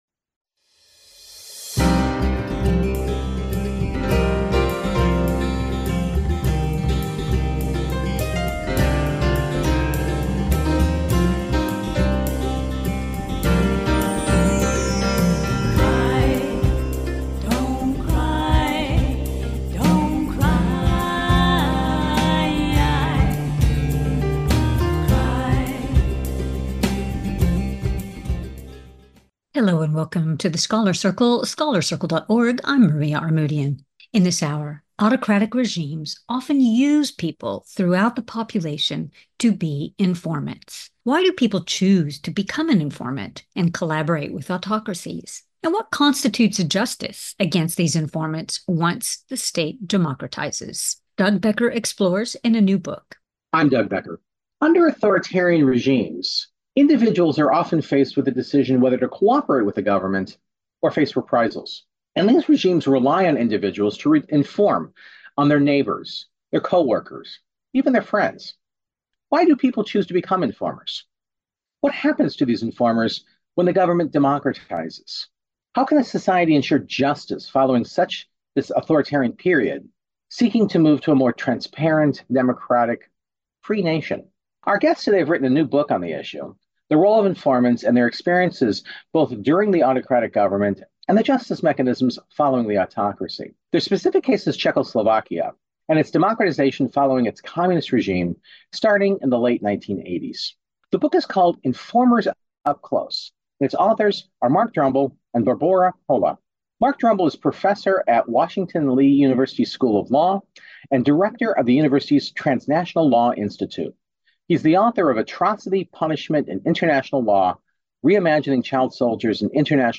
Scholars’ Circle – Recruiting Informers For The State, case-study of Communist Czechoslovakia (1945-1989) – October 12, 2025 | The Scholars' Circle Interviews